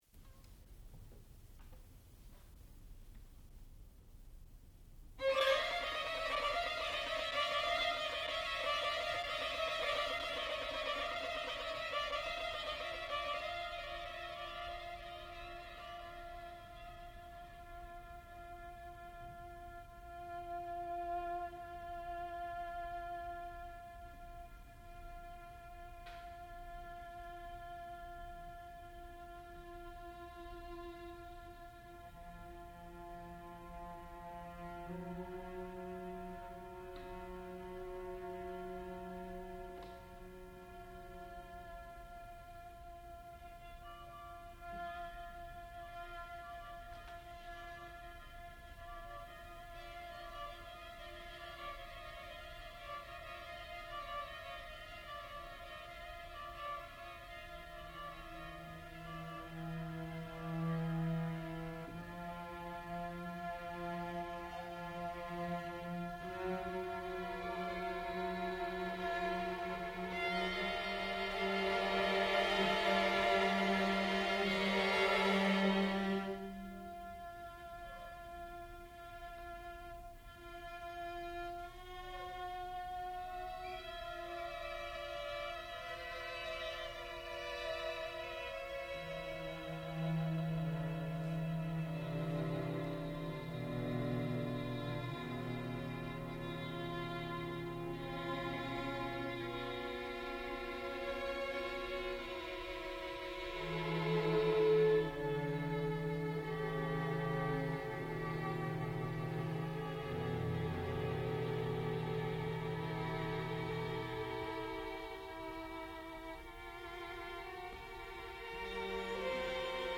sound recording-musical
classical music
viola
violoncello
Graduate Recital